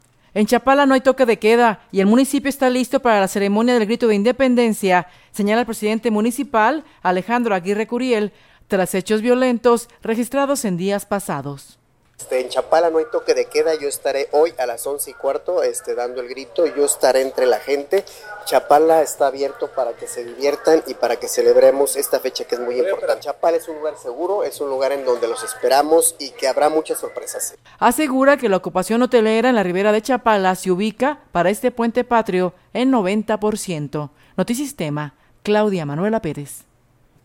En Chapala no hay toque de queda y el municipio está listo para la ceremonia del Grito de Independencia, señala el presidente municipal, Alejandro Aguirre Curiel, tras hechos violentos registrados en días pasados.